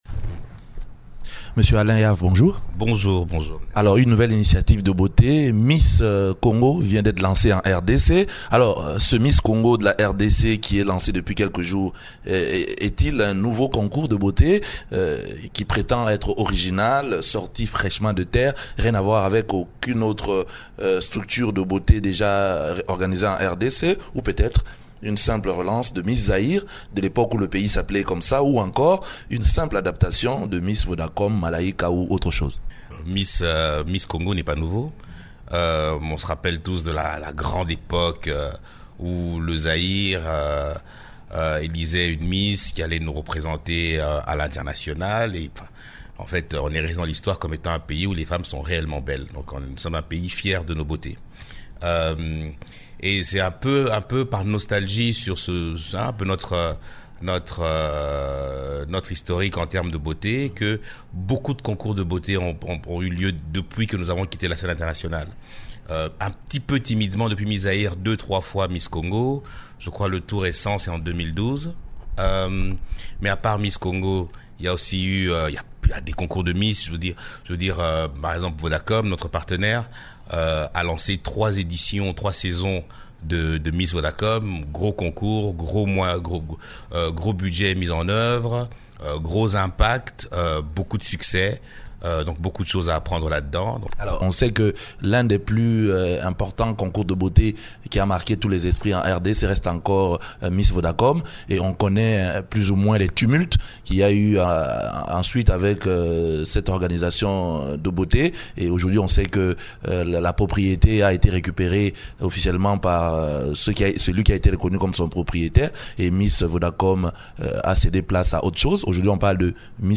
Invité de Radi Okapi mercredi 6 juillet